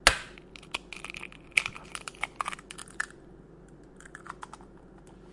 斯密特厨房 " 破解一个鸡蛋01
描述：在Zoom H4n上录制96 KHz 32位立体声
Tag: 厨房 国内的声音 现场记录 烹饪